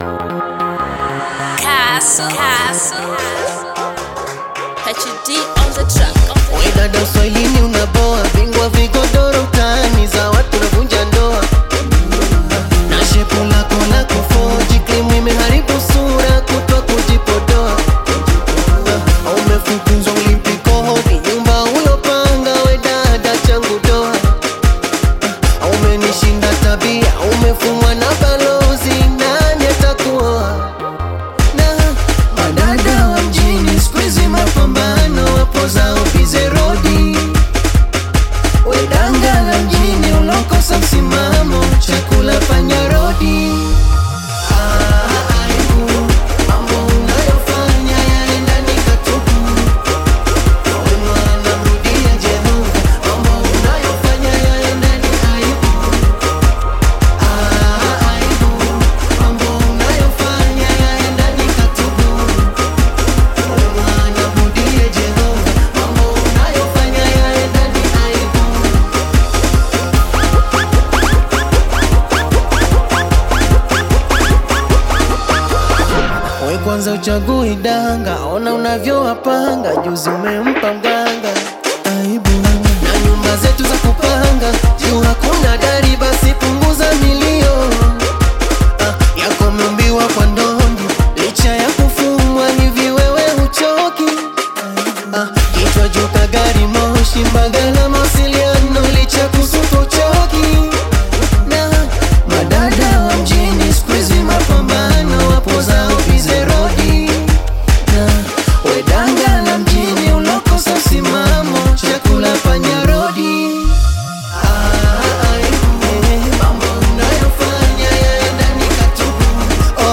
Bongo Flava music track
Tanzanian Bongo Flava artist, singer, and songwriter